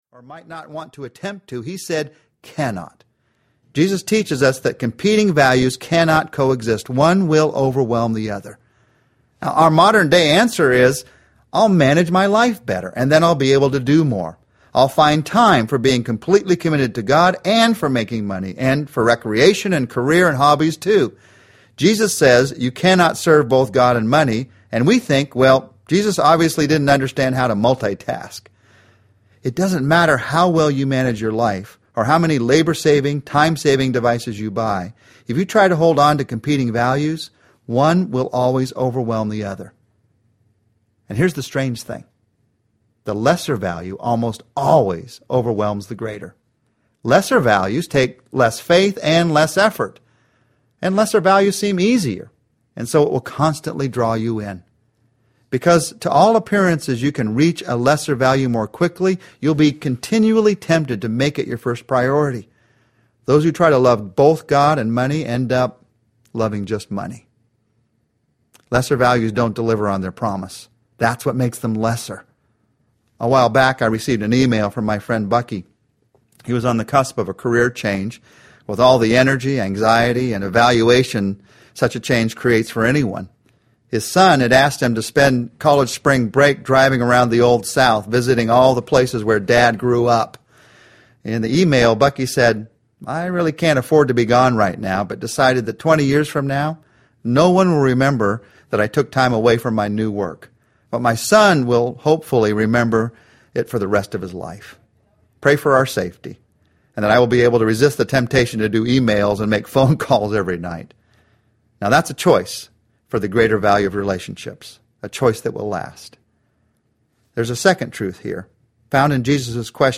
The Relationship Principles of Jesus Audiobook
6.55 Hrs. – Unabridged